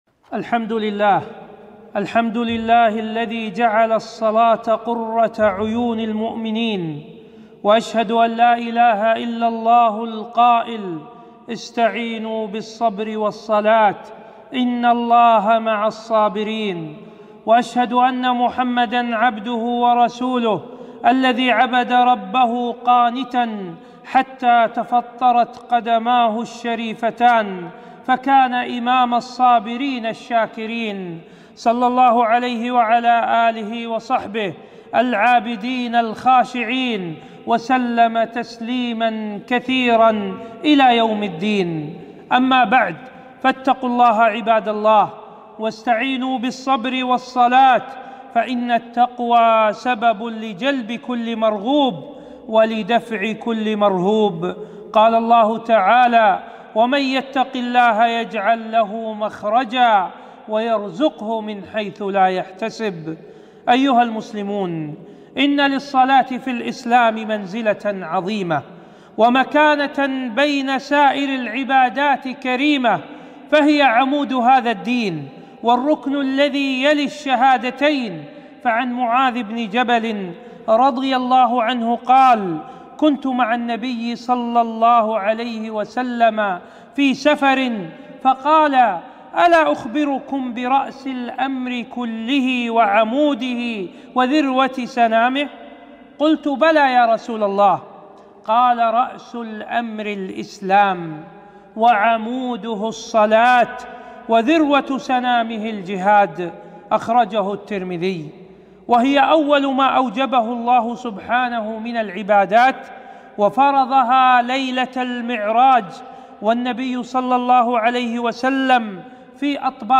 خطبة - خطبة و أقم الصلاة لذكري 9-6-1442